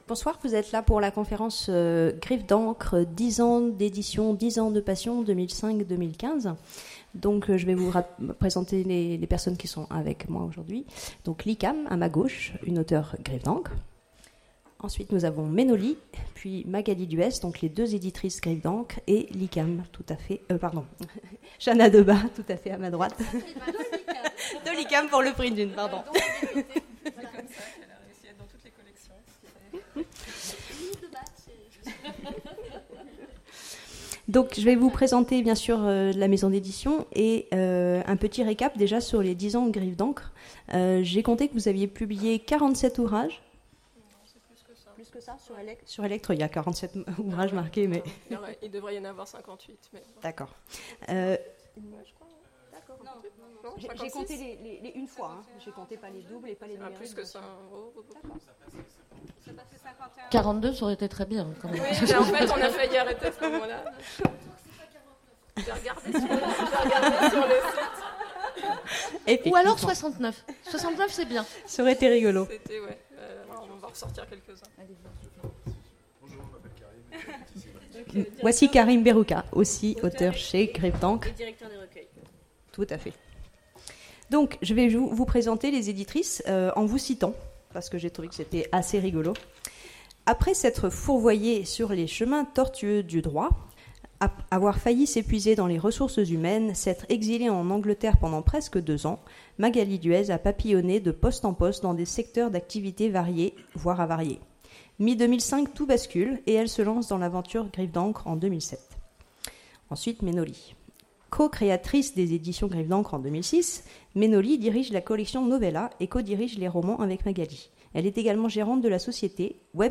Conférence
Mots-clés Rencontre avec une maison d'édition Conférence Partager cet article